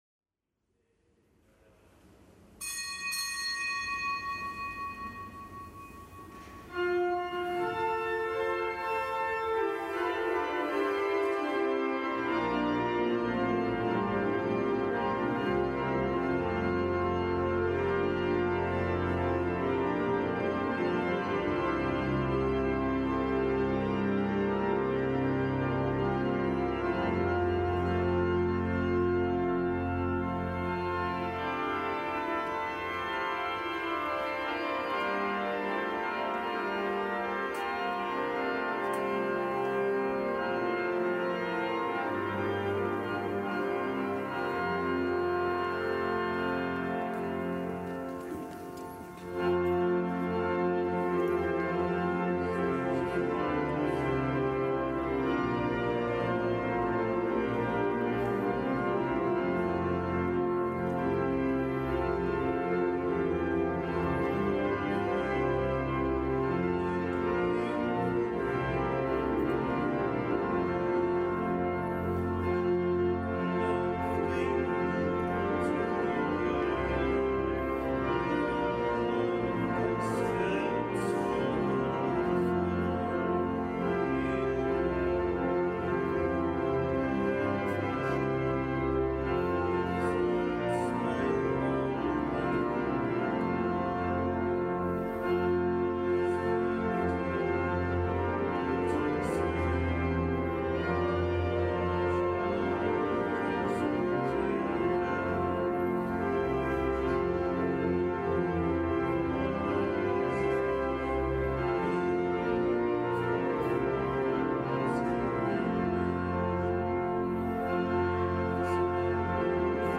Kapitelsmesse aus dem Kölner Dom am Montag der zweiundzwanzigsten Woche im Jahreskreis. Zelebrant: Weihbischof Rolf Steinhäuser.